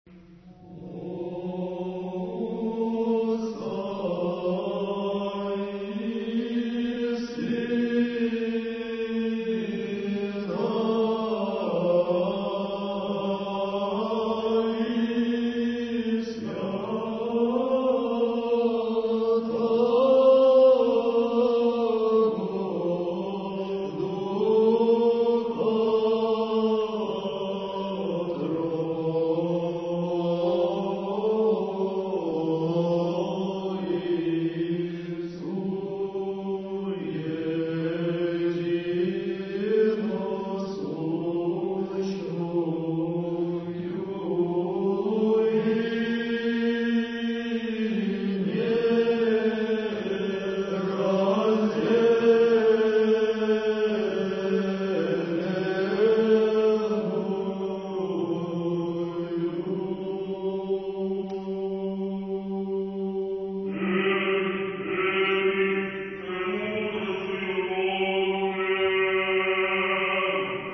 Архив mp3 / Духовная музыка / Русская / Хор Троице-Сергиевой Лавры под управлением архимандрита Матфея (Мормыля) / Литургия в Черниговско-Гефсиманском скиту /